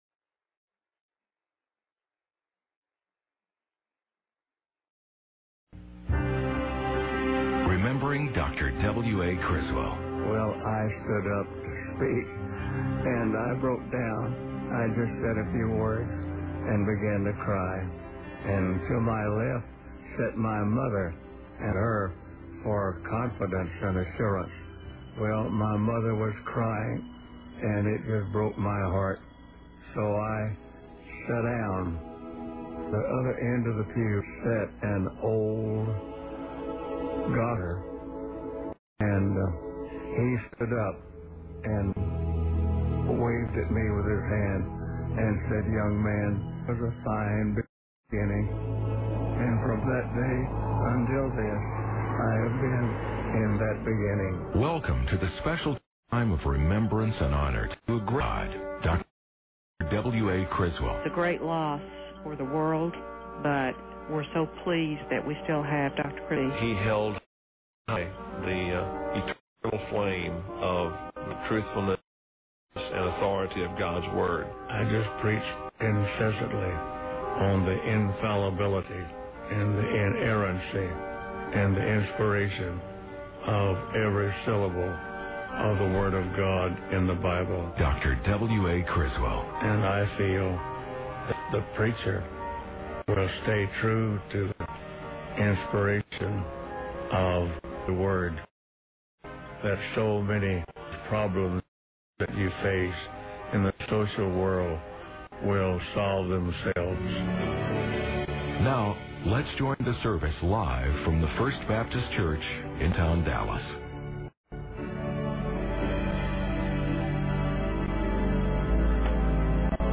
Memorial Service by W.A. Criswell | SermonIndex